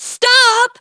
synthetic-wakewords
synthetic-wakewords / stop /ovos-tts-plugin-deepponies_Cozy Glow_en.wav
ovos-tts-plugin-deepponies_Cozy Glow_en.wav